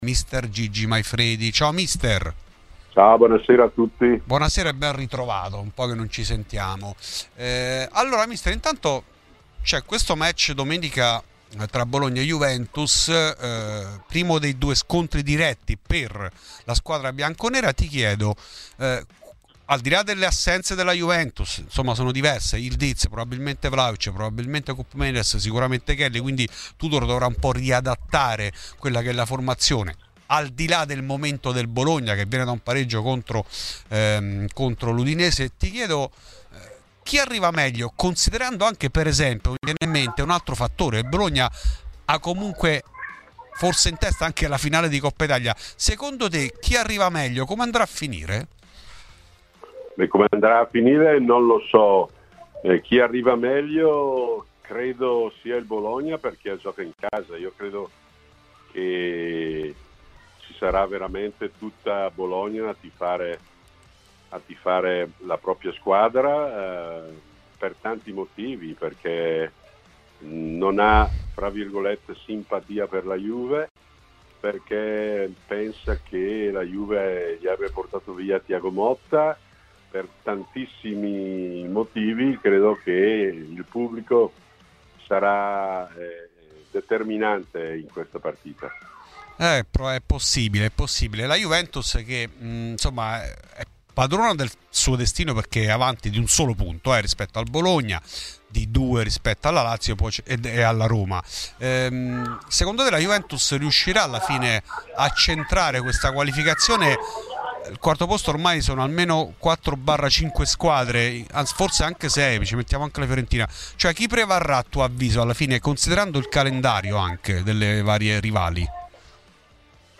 Ai microfoni di Radio Bianconera Maifredi: Bologna-Juve, Motta, Tudor, Conte e Guardiola tra gli argomenti trattati dall'ex allenatore.